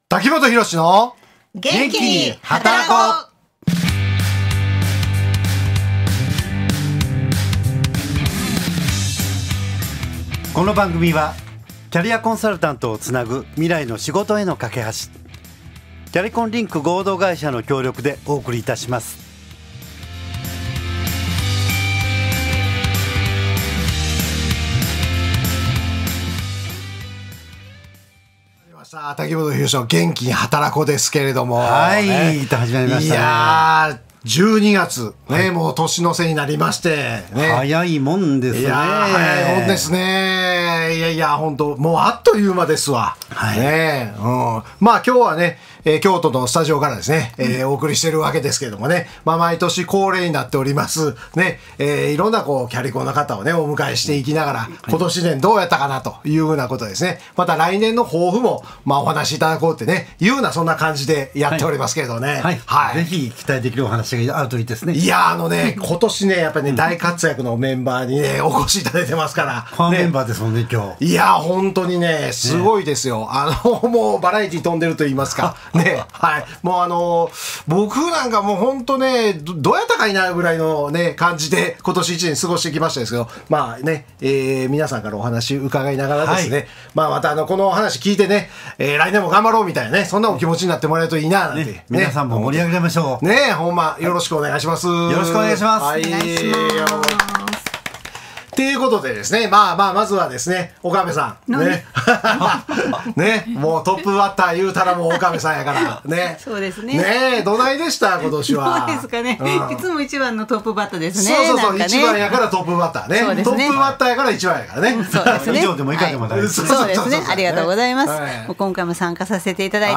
複数の現役キャリアコンサルタントが集まってお送りした師走の公開放送の放送回。
ラジオならではの距離感で交わされる本音トークは、笑いあり、気づきあり、そして「自分も来年もっと挑戦してみよう」と背中を押されるような温かい時間です。